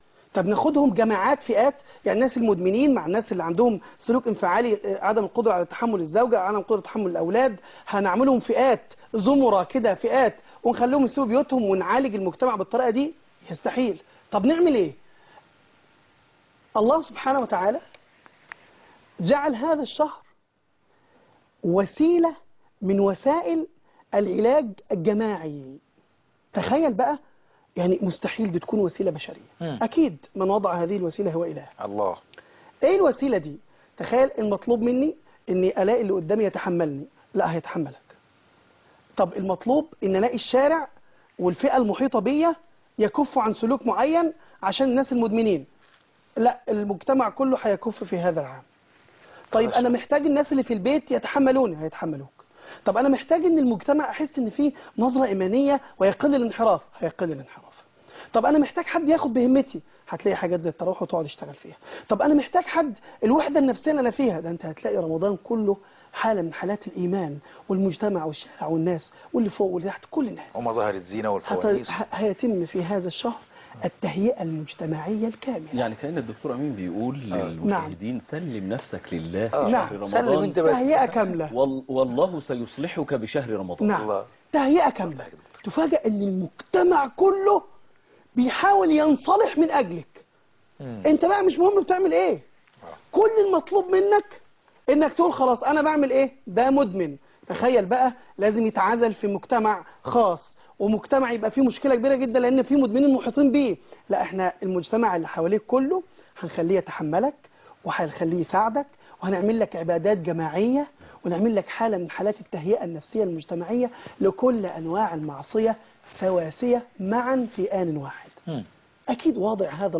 لقاء